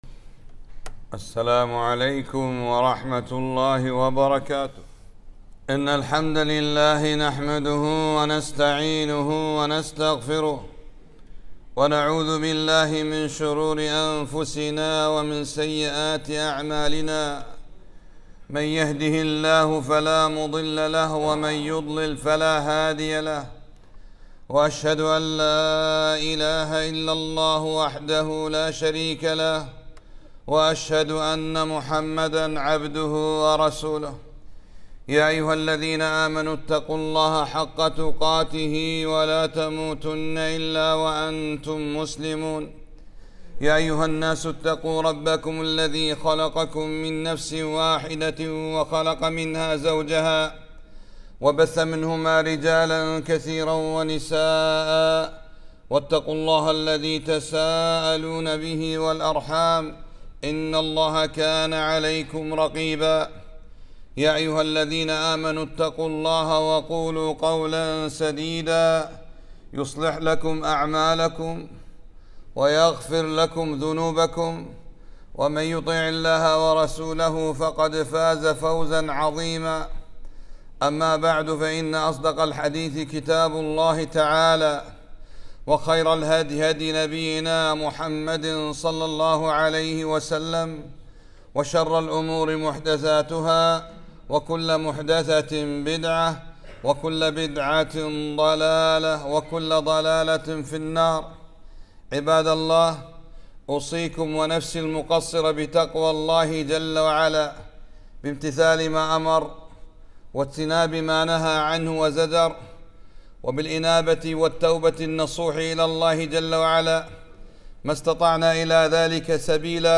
خطبة - قال تعالى (إذا تداينتم بدينٍ إلى أجلٍ مسمى فاكتبوه)